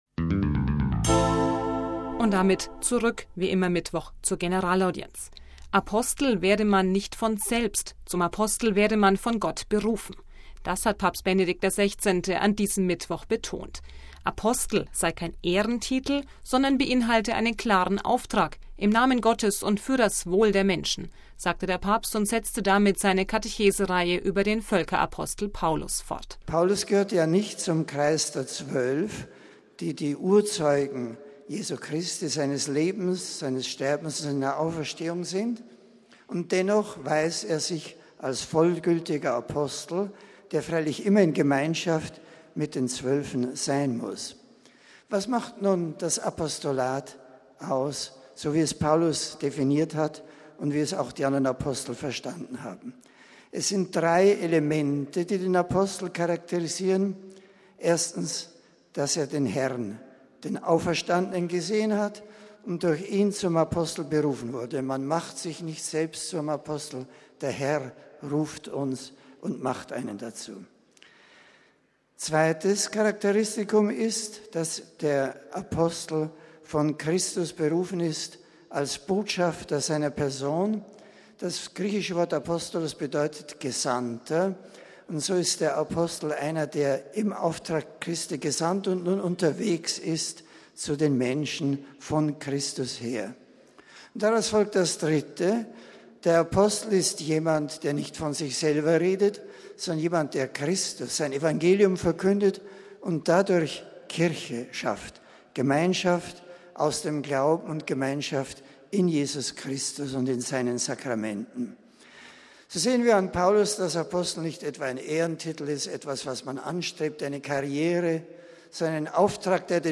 Rund 9.000 Pilger waren in der Audienzhalle versammelt, darunter Journalisten aus aller Welt, die an einem von der Opus-Dei-Universität ausgerichteten Seminar für kirchlichen Journalismus teilnehmen und Mitglieder des britischen Unterhauses aller Parteien.